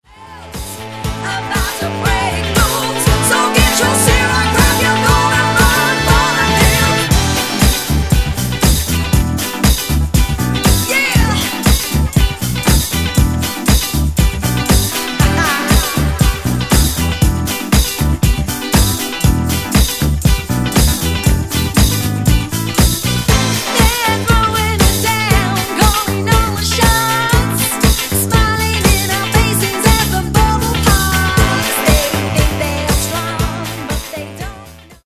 Genere:   Funky | Soul